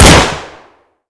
glock18-2.wav